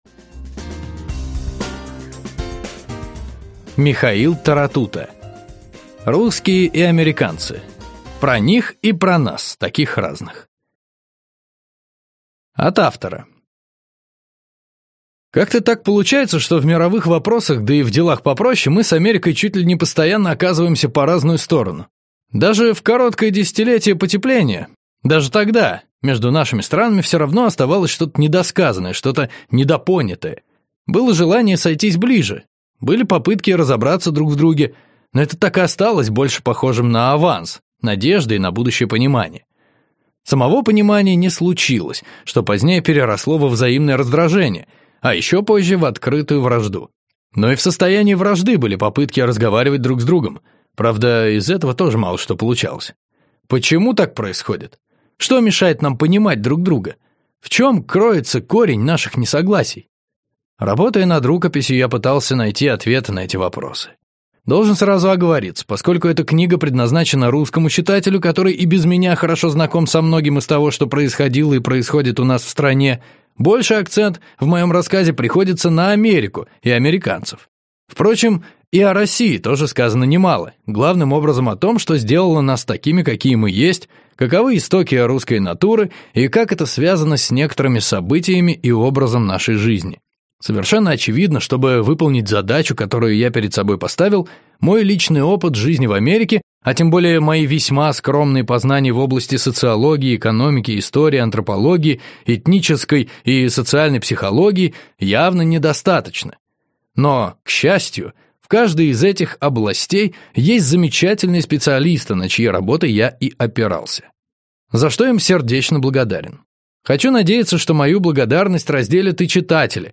Аудиокнига Русские и американцы. Про них и про нас, таких разных | Библиотека аудиокниг